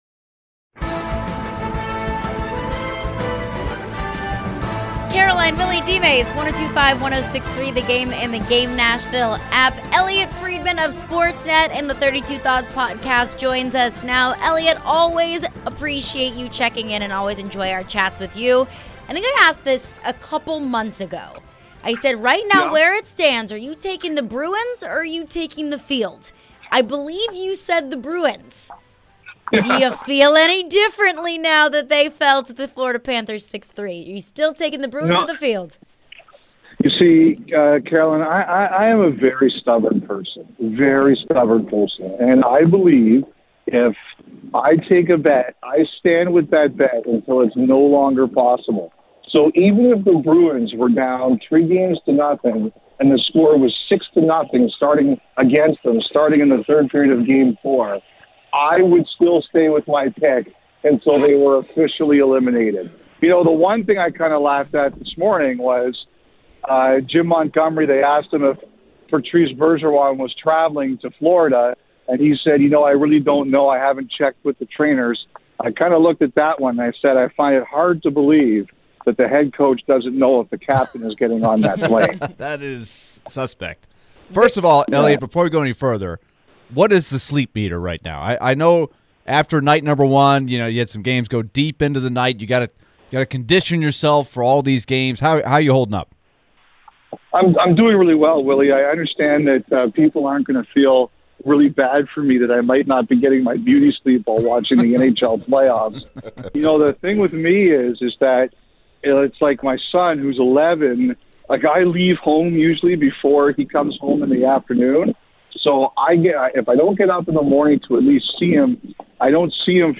Elliotte Friedman Interview (4-21-23)